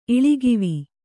♪ iḷigivi